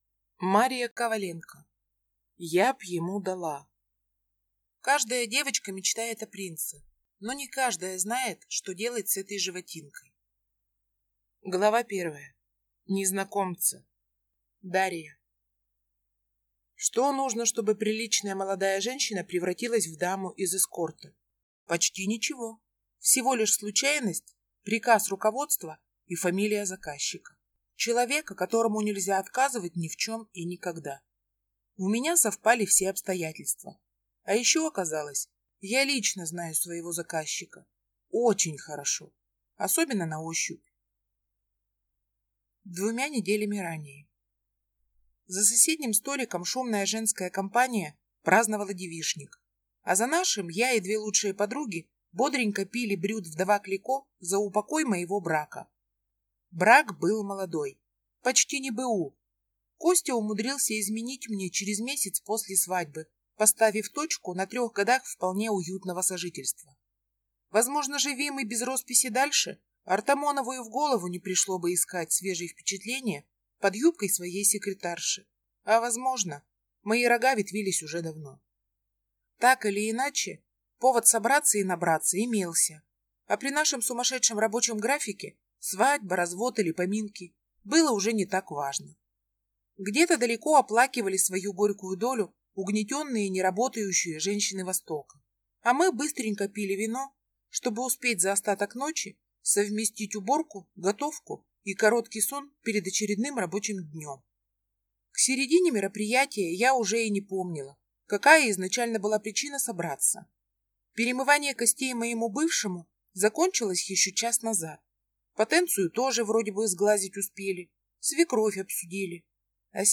Аудиокнига Я б ему дала | Библиотека аудиокниг